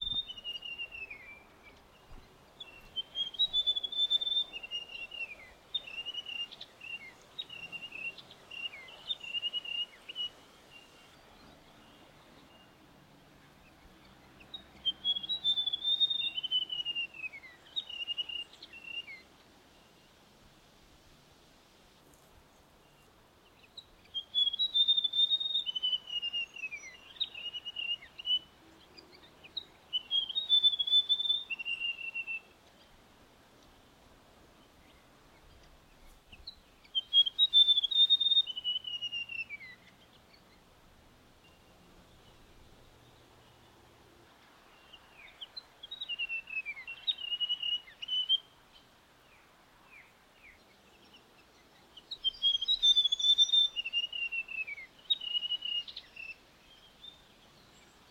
White-throated Gerygone
It’s a White-throated Gerygone (pronounced jer-rig-ony, not jerry-gone) and it has what is surely one of the sweetest songs of all our native birds.
However, we were lucky to come across one at the Yea Wetlands recently which was so absorbed with feeding and singing on low branches, it was quite unconcerned by our presence just meters away.
Click on the icon below to hear its silvery song.
w-t-gerygone.mp3